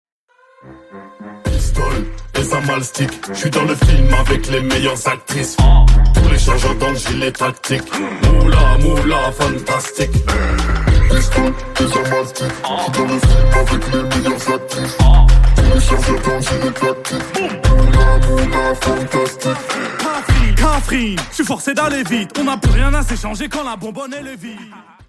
Hip Hop ,Uncategorized